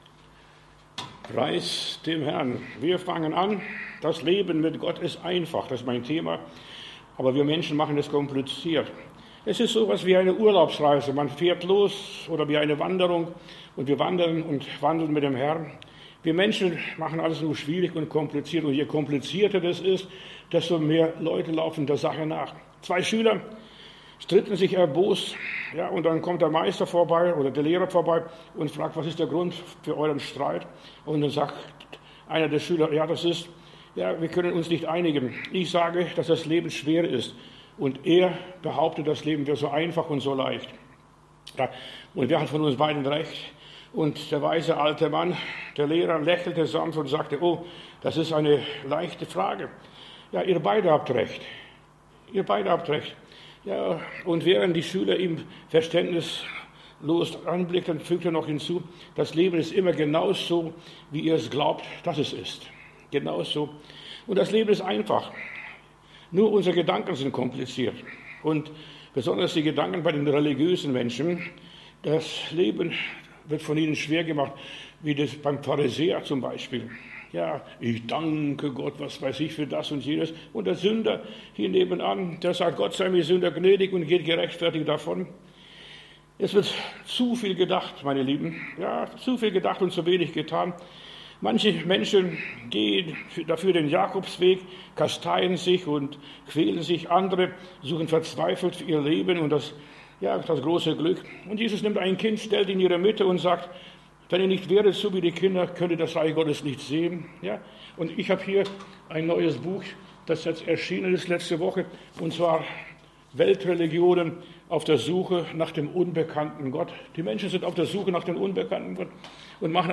Predigt herunterladen: Audio 2024-11-13 Das Leben mit Gott ist einfach Video Das Leben mit Gott ist einfach